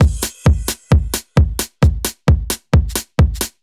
Index of /musicradar/uk-garage-samples/132bpm Lines n Loops/Beats
GA_BeatD132-05.wav